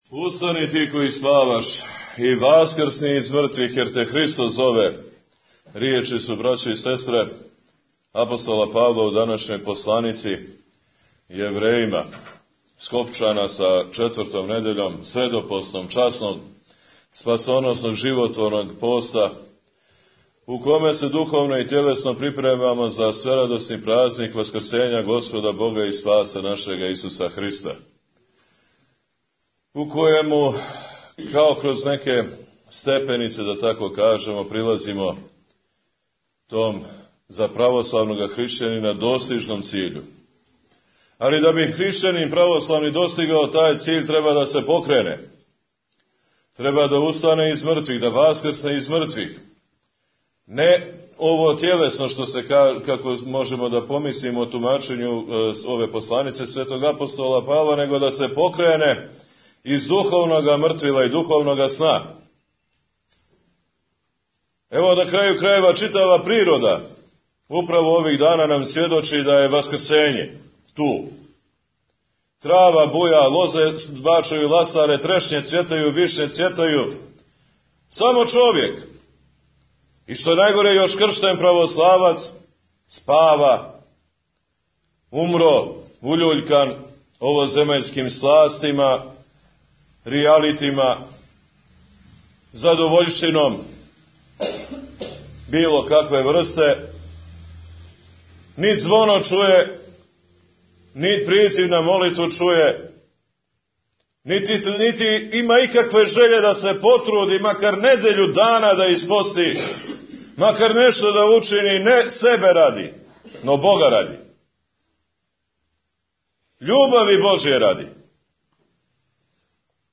Tagged: Бесједе Наслов